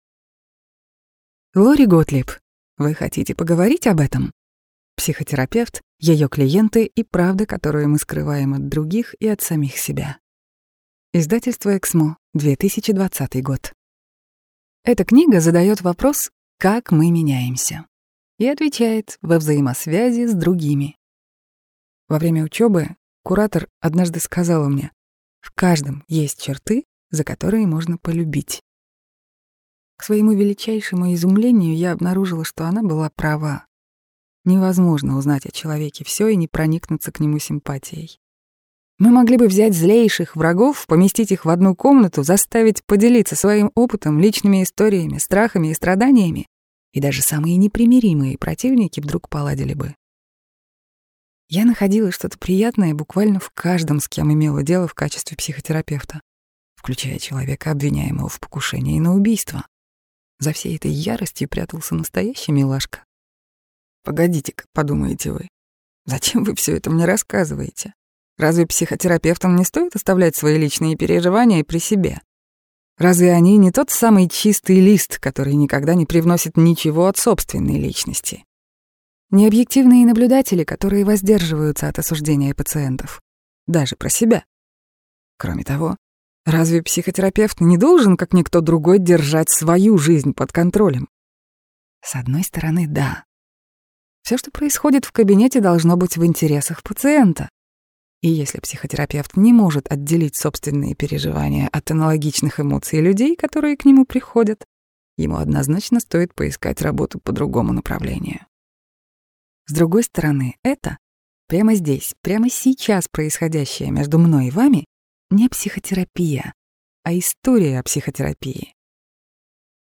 Аудиокнига Вы хотите поговорить об этом? Психотерапевт. Ее клиенты. И правда, которую мы скрываем от других и самих себя | Библиотека аудиокниг